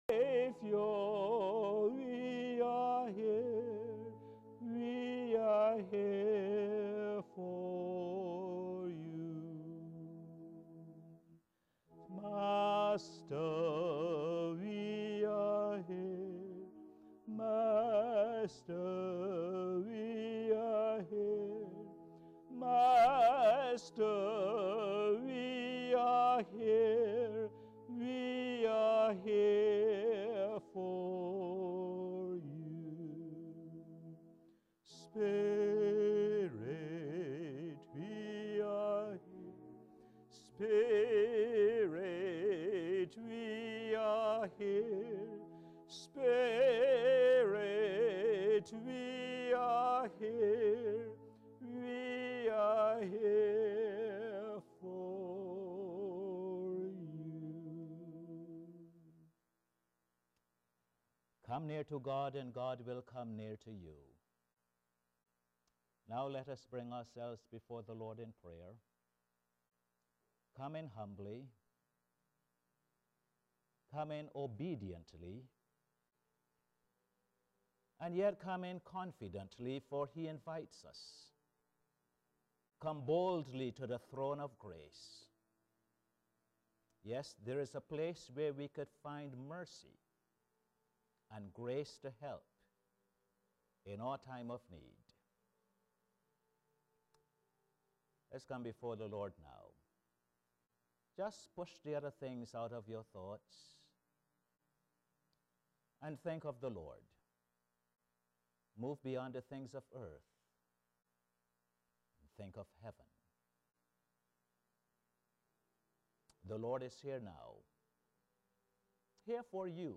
Posted in Sermons on 17.